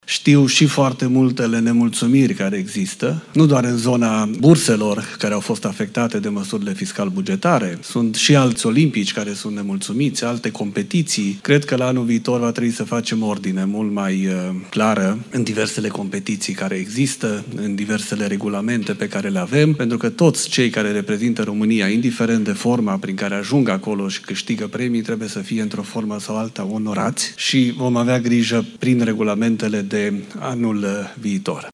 Ministrul Educației, Daniel David: „Anul viitor trebuie să facem ordine mult mai clară în diversele competiții care există”
Prezent la festivitatea de premiere a olimpicilor de la Palatul Copiilor din București, ministrul Educației, Daniel David, a spus că vor fi corectate regulamentele de anul viitor astfel încât să poată fi recompensați toți cei care reprezintă România la concursuri și câștigă premii.